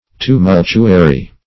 Tumultuary \Tu*mul"tu*a*ry\ (t[-u]*m[u^]l"t[-u]*[asl]*r[y^];